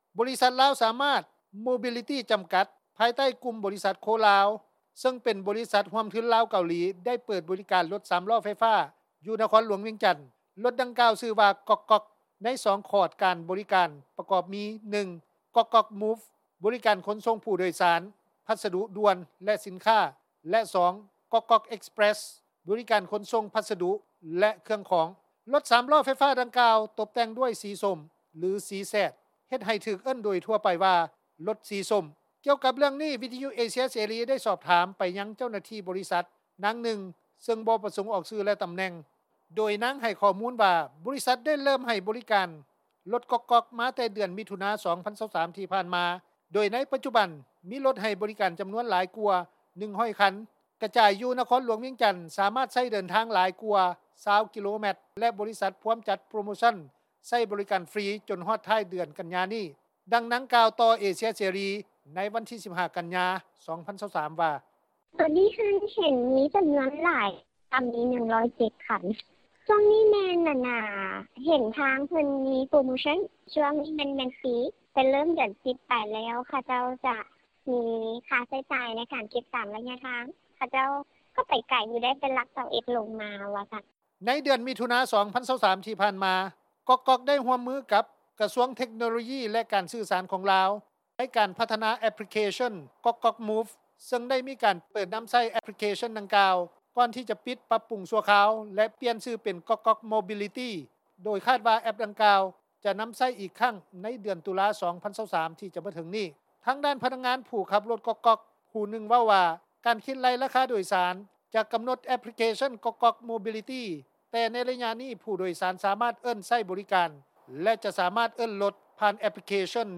ຊາວບ້ານ ຜູ້ນຶ່ງ ຢູ່ນະຄອນຫຼວງວຽງຈັນ ເຊິ່ງໄດ້ມີໂອກາດຊົມໃຊ້ ຣົຖກ໋ອກກ໋ອກແລ້ວ ກ່າວວ່າ:
ຊາວບ້ານ ນາງນຶ່ງ ຢູ່ນະຄອນຫຼວງວຽງຈັນ ກ່າວວ່າ:
ຜູ້ປະກອບການຣົຖຕຸກຕຸກ ຜູ້ນຶ່ງ ຢູ່ນະຄອນຫຼວງວຽງຈັນ ກ່າວວ່າ: